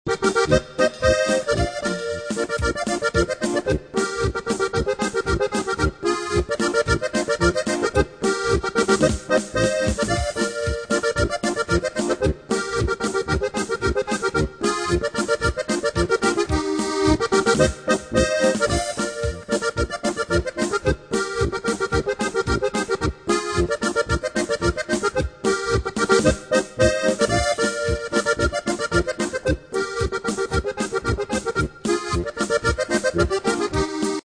die een Tiroler Duo vormen